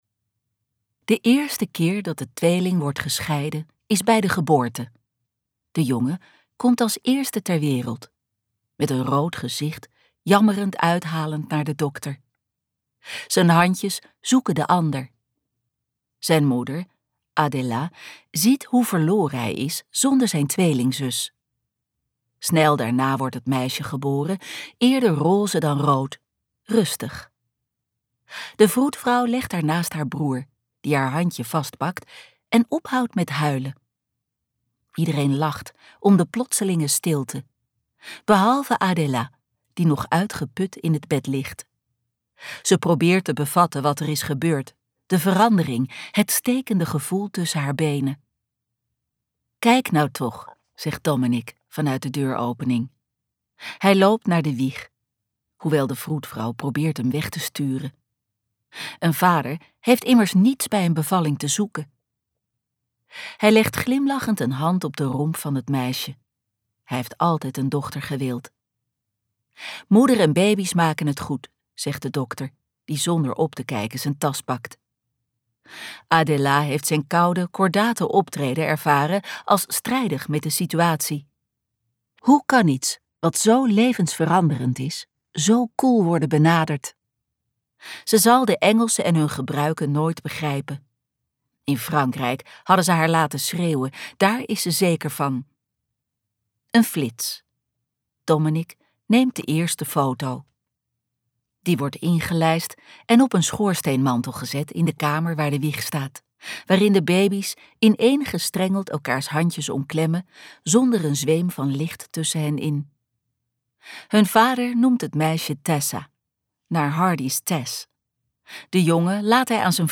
De schok van het licht luisterboek | Ambo|Anthos Uitgevers